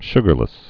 (shgər-lĭs)